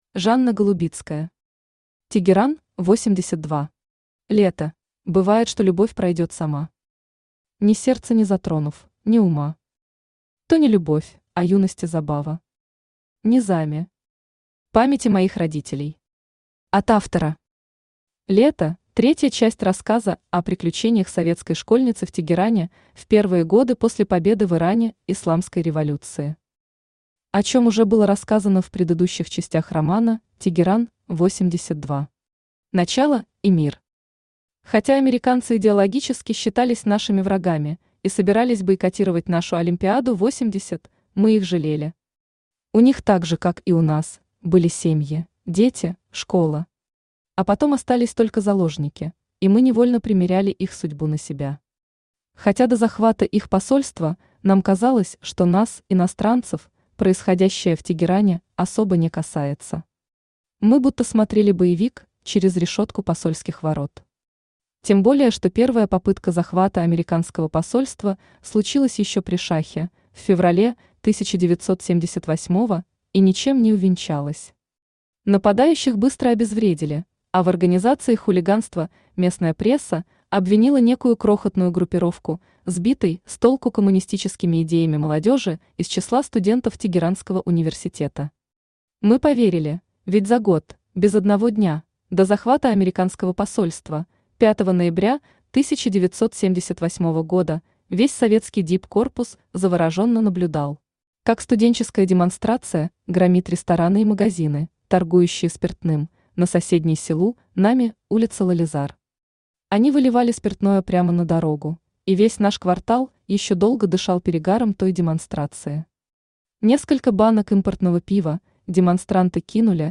Аудиокнига Тегеран-82.
Aудиокнига Тегеран-82. Лето Автор Жанна Голубицкая Читает аудиокнигу Авточтец ЛитРес.